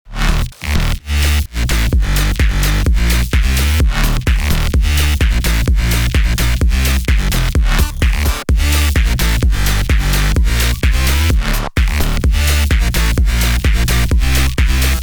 BASS HOUSE KITS
ACE [FULL KIT]